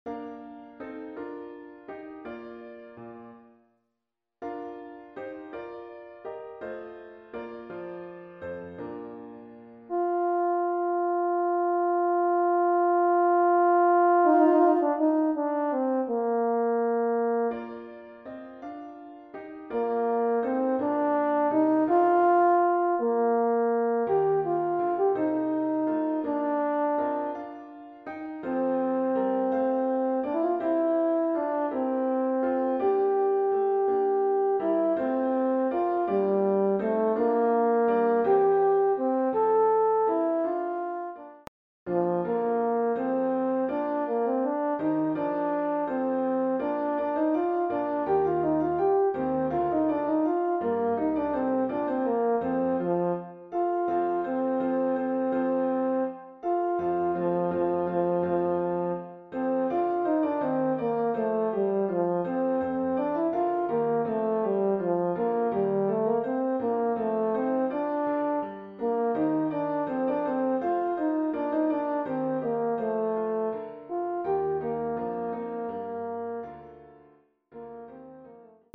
Voicing: Horn Solo